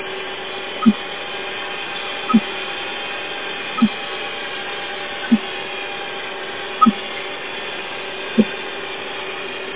Sperm Whale (Physeter macrocephalus)